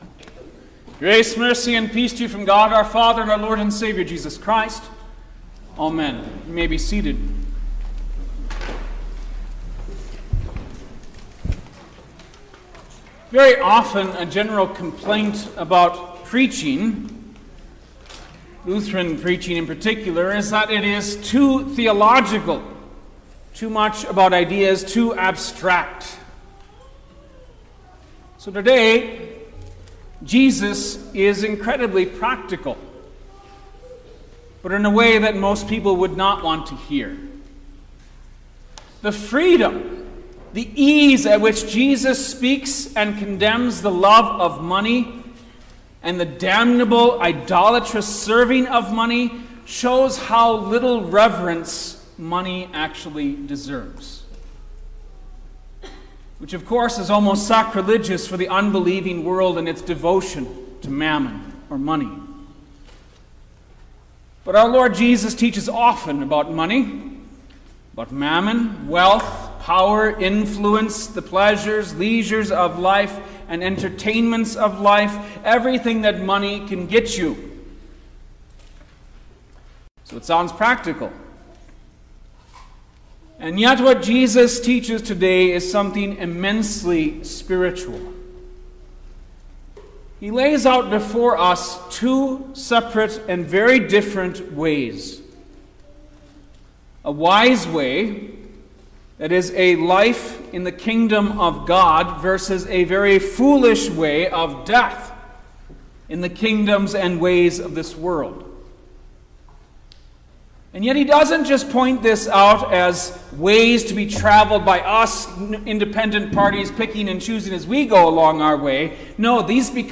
September-8_2024_Fifteenth-Sunday-after-Trinity_Sermon-Stereo.mp3